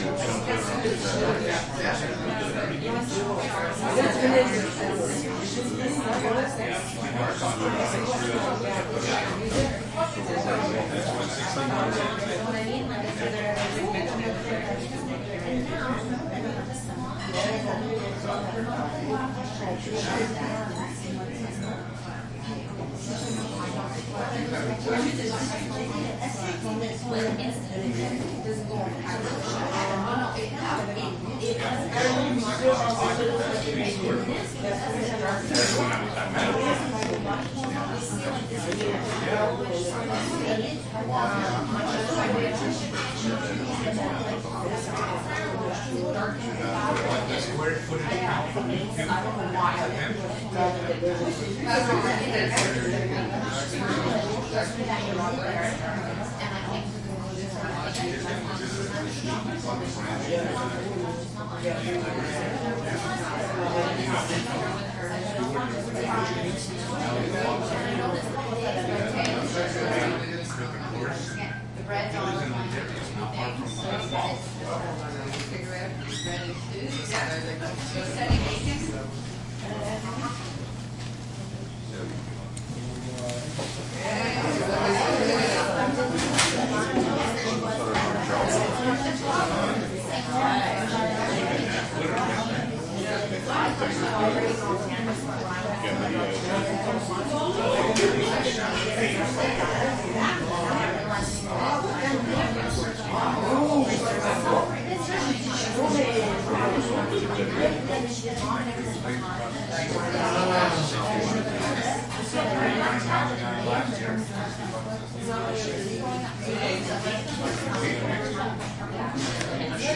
蒙特利尔 " 人群中的小光华中餐馆2 蒙特利尔，加拿大
描述：人群int小光walla中国restaurant2蒙特利尔，Canada.flac
Tag: 重量轻 INT 餐厅 中国人 人群中 沃拉